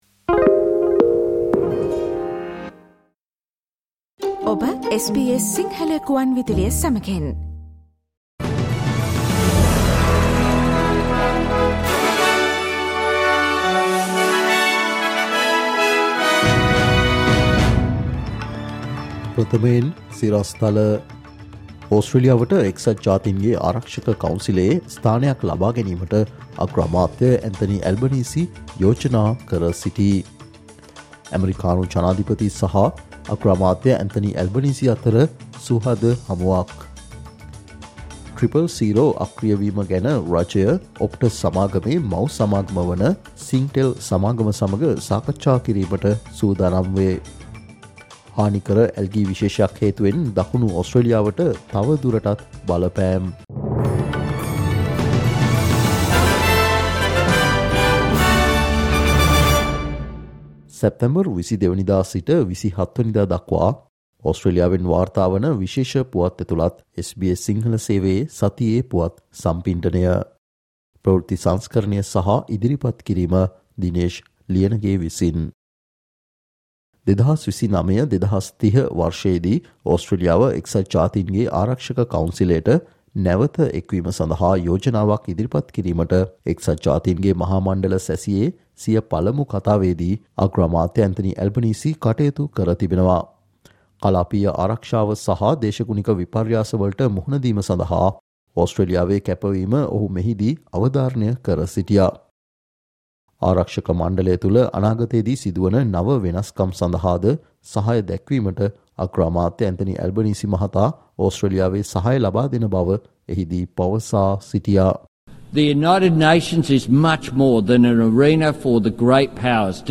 සැප්තැම්බර් 22 සිට සැප්තැම්බර් 26 වනදා දක්වා වන මේ සතියේ ඕස්ට්‍රේලියාවෙන් වාර්තාවන පුවත් ඇතුලත් සතියේ පුවත් ප්‍රකාශයට සවන් දෙන්න